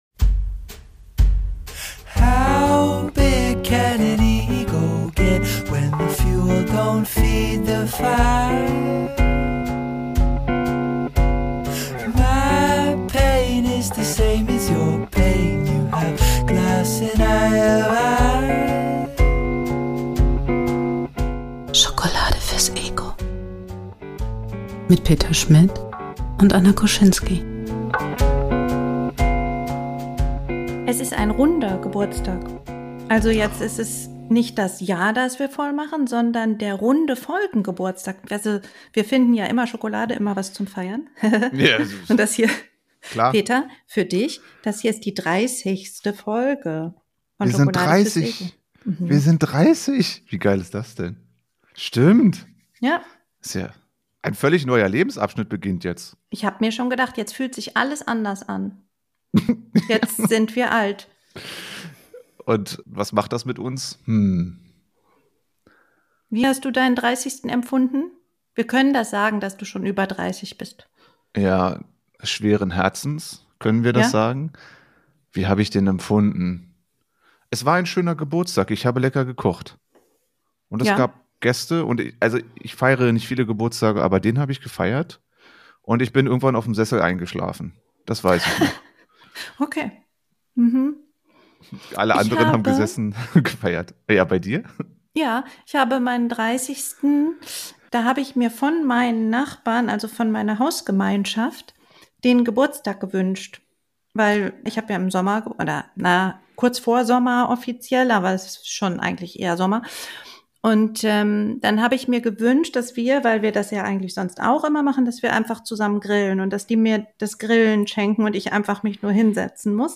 Es wird verbissen, gnadenlos und manchmal sogar laut.